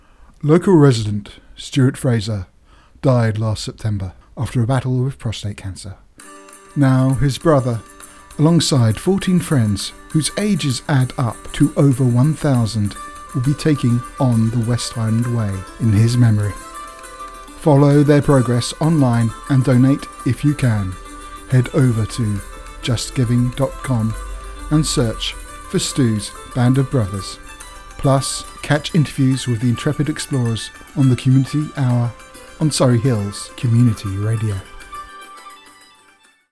Also, and with our profound thanks for their support, Surrey Hills Community Radio has released this advert for us.